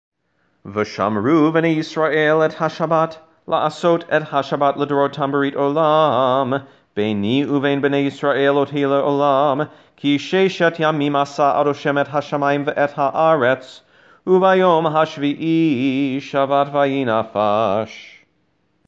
Service Recordings – Weekday Maariv and Shabbat Shacrit
Shabbat Shacrit